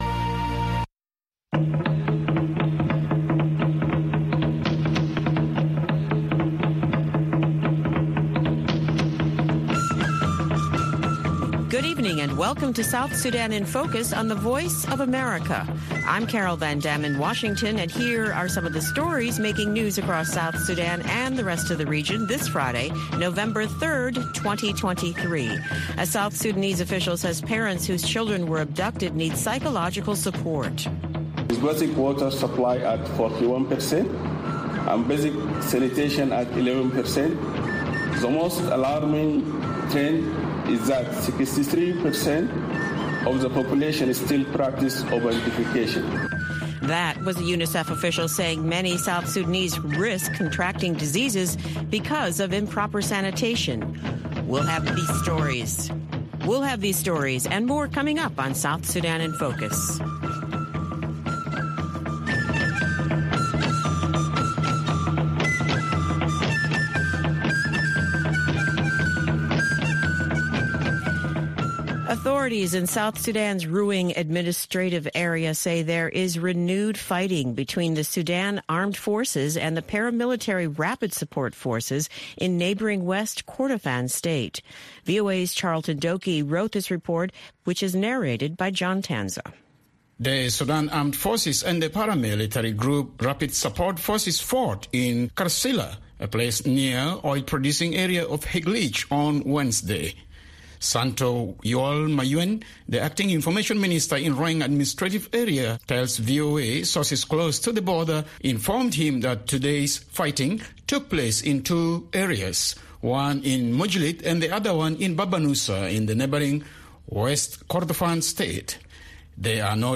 South Sudan in Focus airs at 7:30 pm in Juba (1630 UTC) and can be heard on FM stations throughout South Sudan, on shortwave, and on VOA’s 24-hour channel in Nairobi at 8:30 pm.